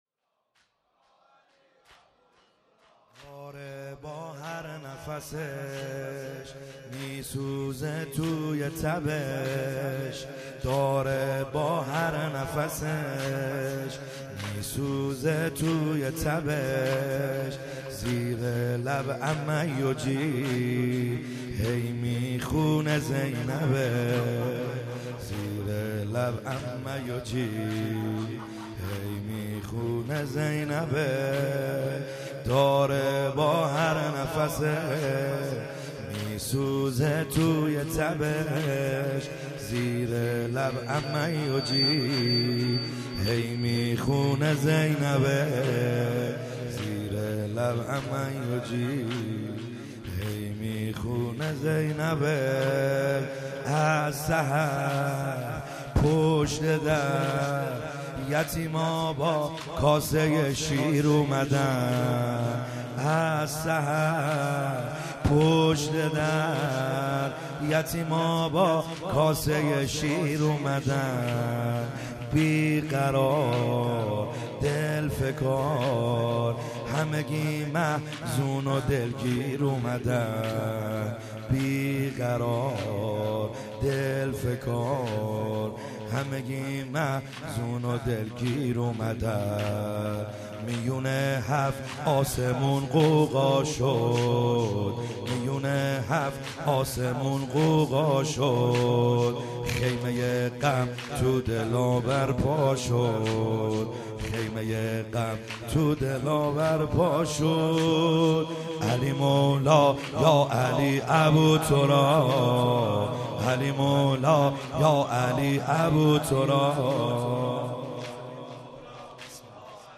خیمه گاه - بیرق معظم محبین حضرت صاحب الزمان(عج) - زمینه | داره با هر نفسش